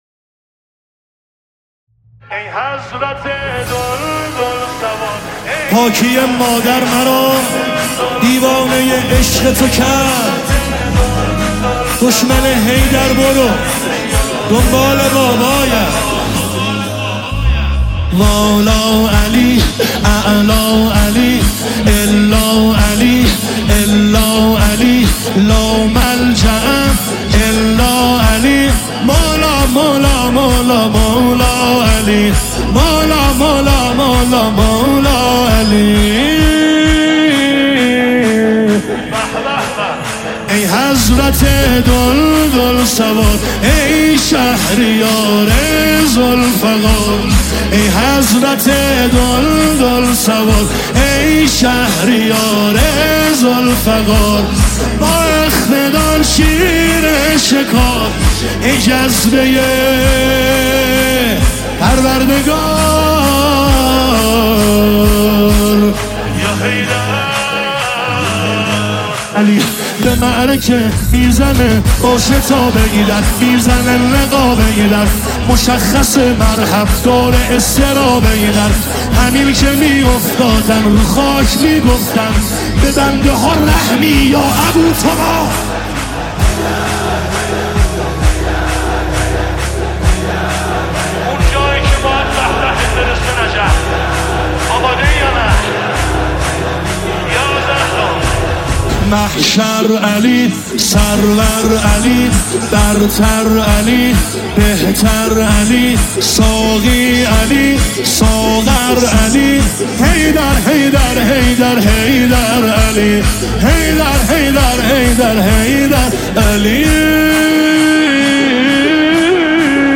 نماهنگ دلنشین
مداحی مذهبی